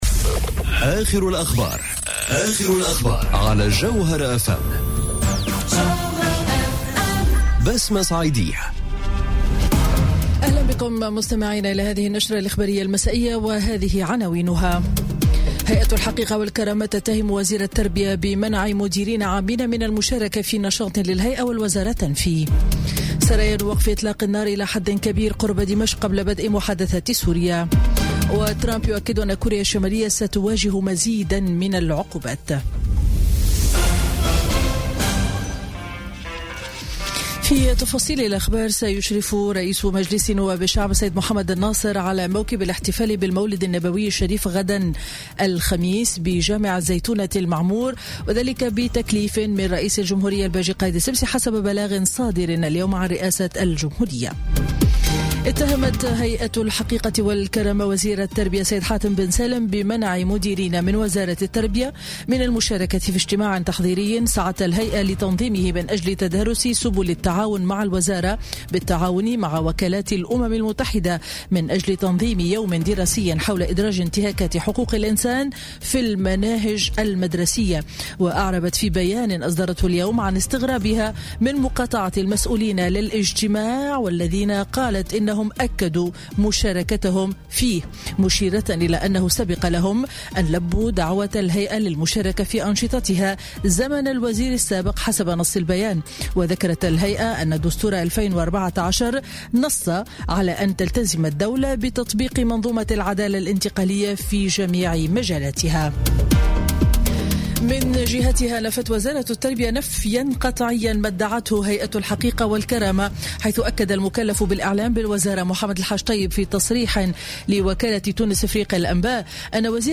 Journal Info 19h00 du Mercredi 29 Novembre 2017